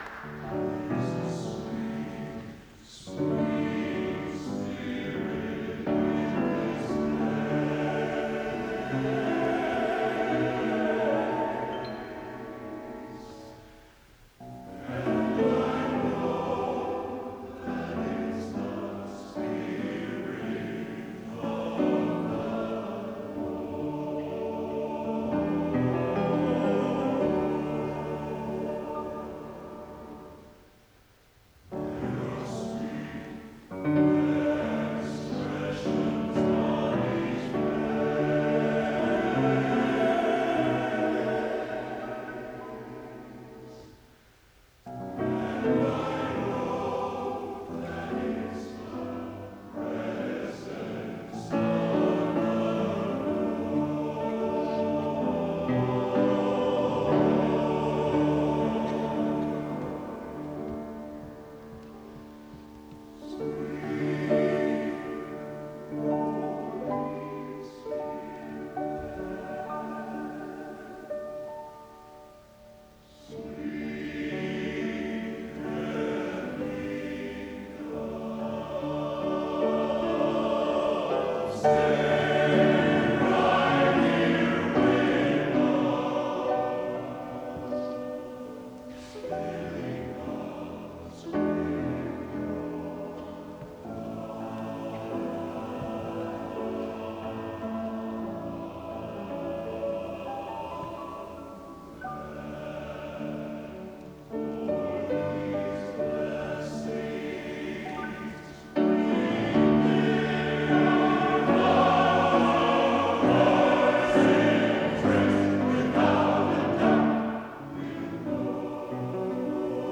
Collection: Broadway Methodist, 1993